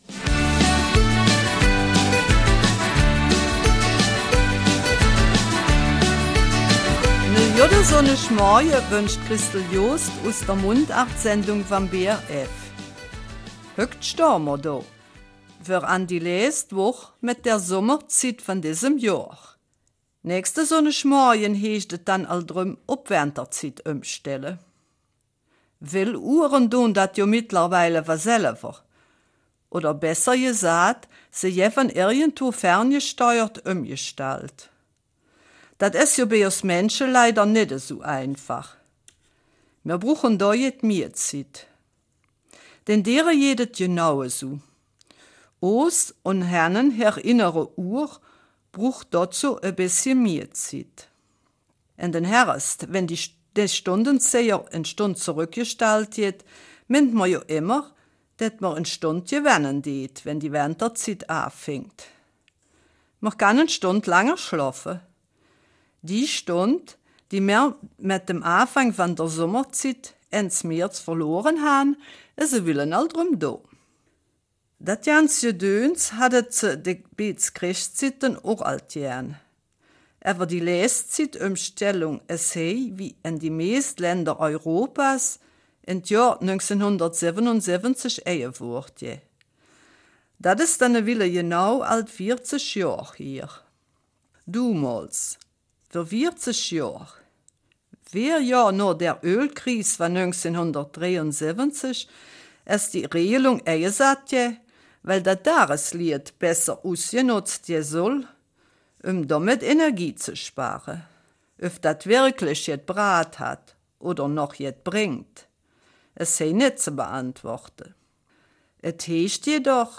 Eifeler Mundart: Die Winterzeit beginnt